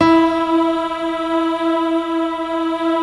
SI1 PIANO09R.wav